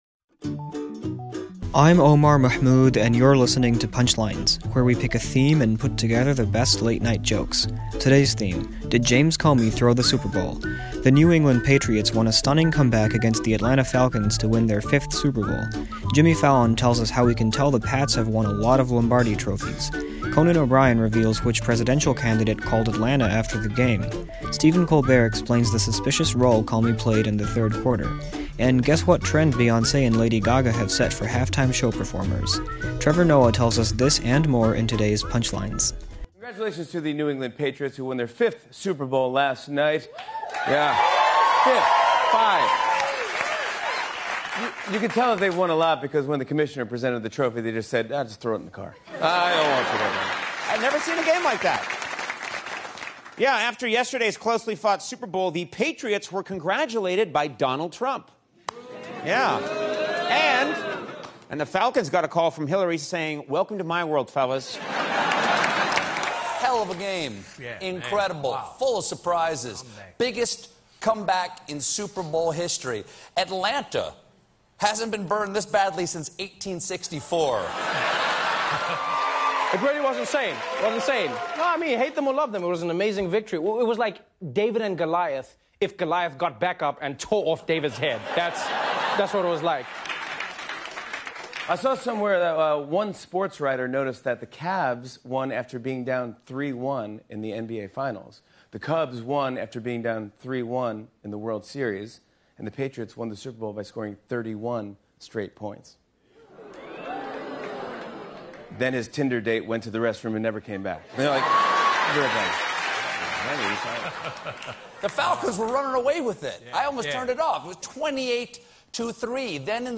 The late-night comics talk about that astonishing win and halftime show.